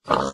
Sound / Minecraft / mob / pig / say1.ogg